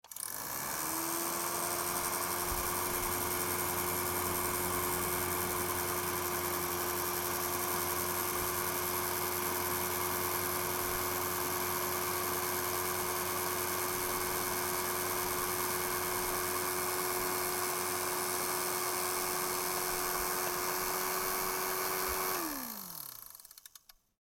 EDU III, Siemens hair dryer